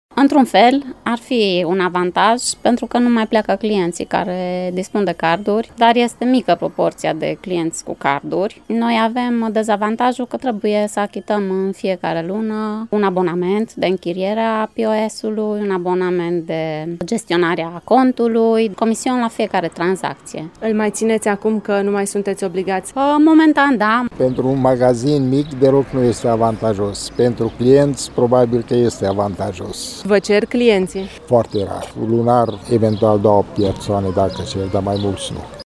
Comercianții mureșeni spun că deținerea unui POS nu le-a crescut semnificativ numărul de clienți și că această modalitate de plată este dezavantajoasă pentru magazine din cauza comisioanelor: